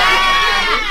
TM88 ClubVox.wav